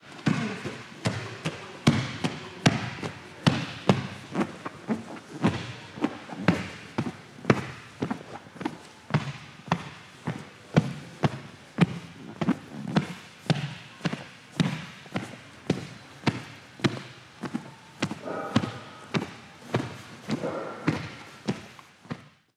Andar sobre un tatami